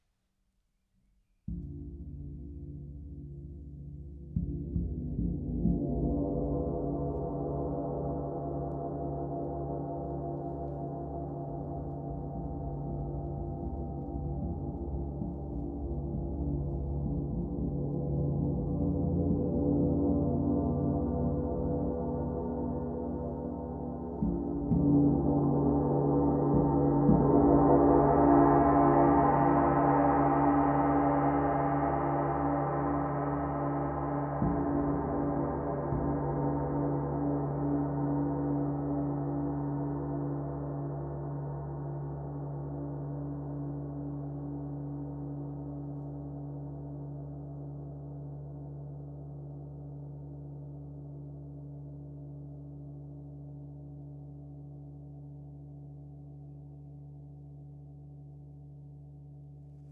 Gong Samatha R. Bonneau • 96 cm
D’un diamètre généreux de 96 cm, ce gong développe une richesse harmonique impressionnante avec des sons profonds.
Chaque frappe révèle des paysages sonores immersifs, invitant à une exploration sensorielle et à un véritable lâcher-prise.
• Sonorité profonde et enveloppante
Extrait frappes